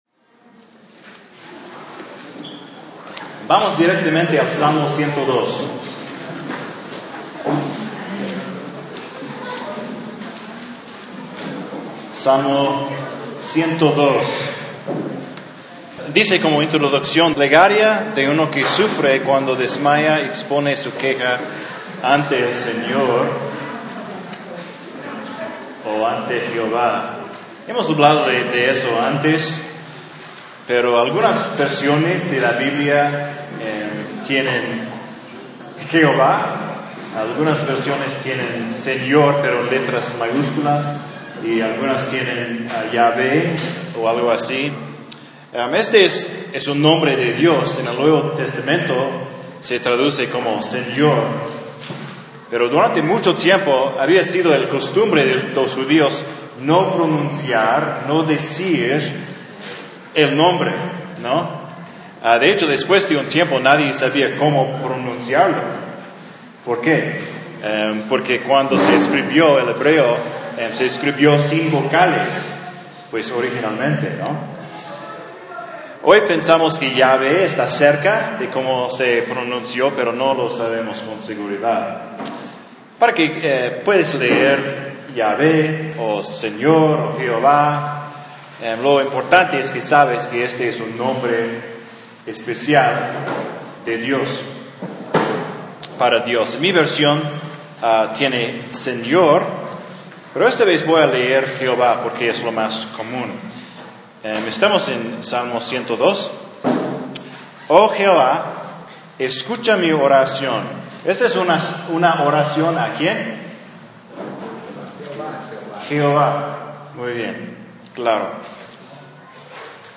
Un sermón de Hebreos 1.